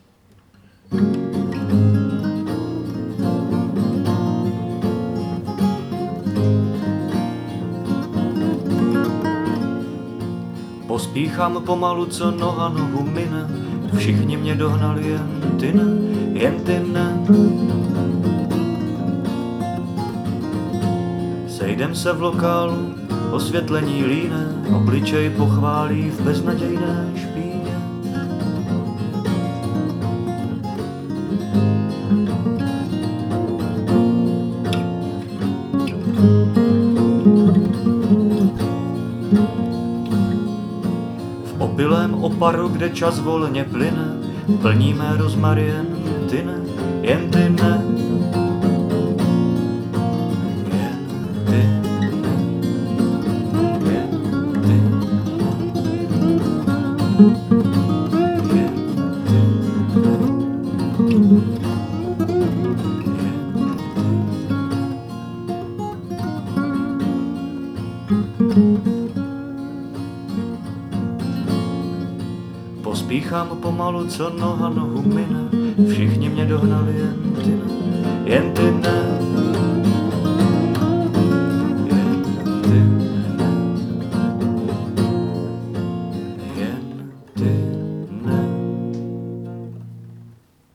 ...blues...
Má příjemný hlas.